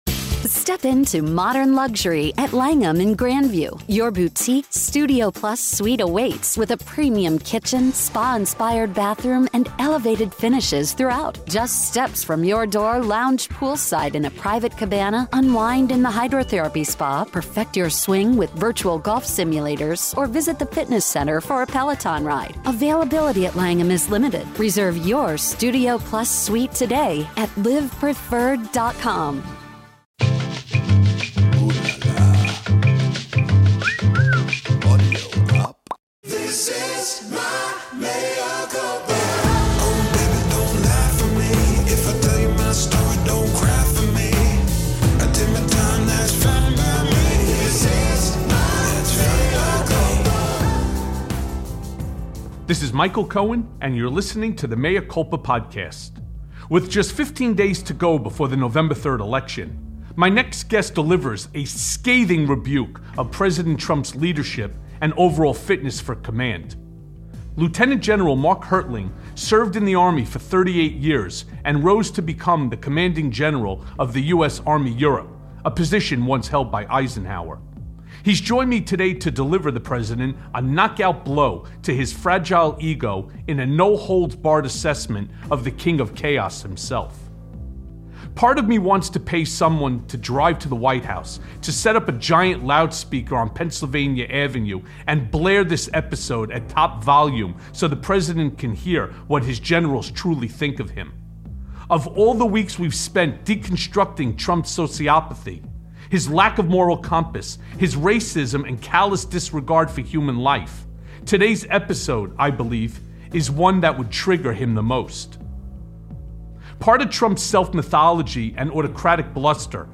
This eye-opening episode of Mea Culpa features Lt. General Mark Hertling, former Commanding General of the US Army Europe, delivering a scathing assessment of Trump's unfitness for command. We also learn how the entire command structure of the military loathes their commander in chief.